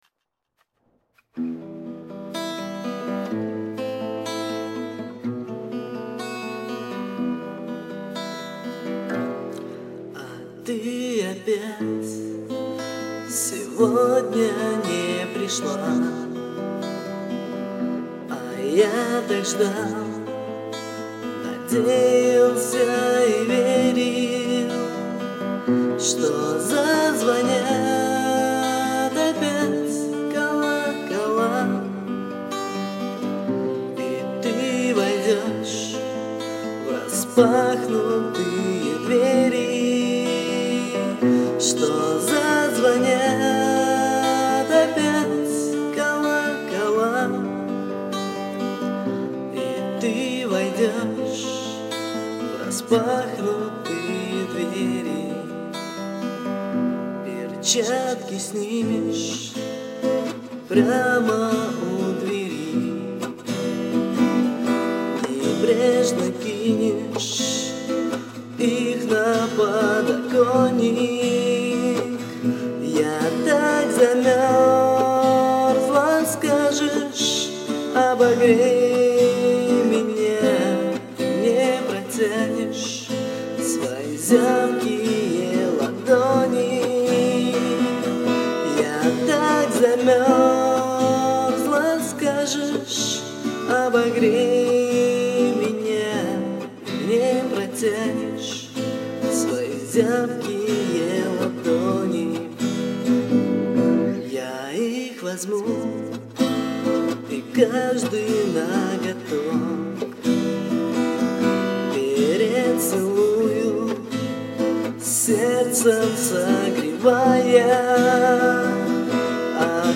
Ключевые слова: О ней, грусть, печаль